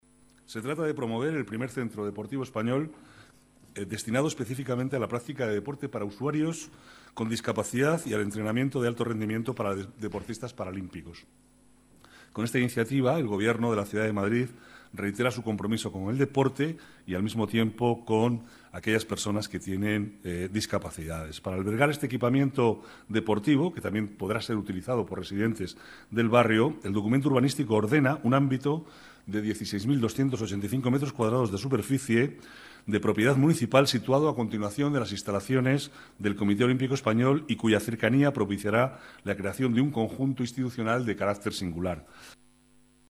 Nueva ventana:Declaraciones de Manuel Cobo, vicealcalde de Madrid